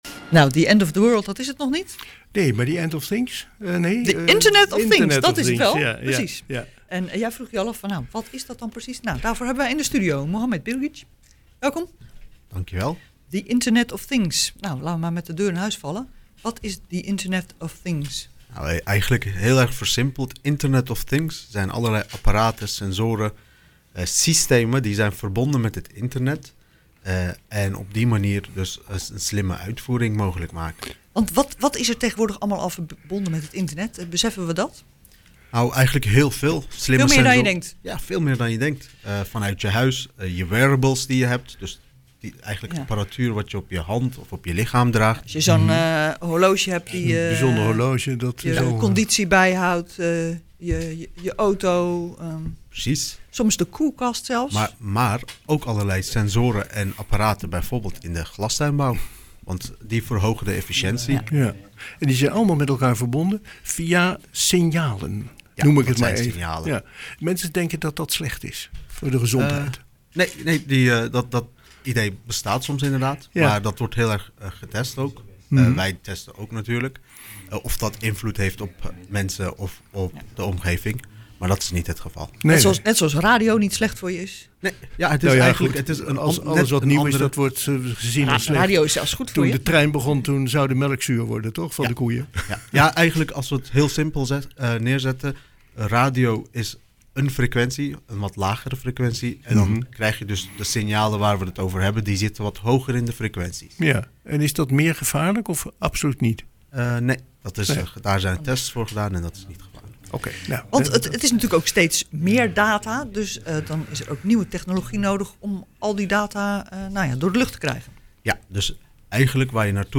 Missed it? You can listen back to the broadcast down below (in Dutch, broadcast of August 22).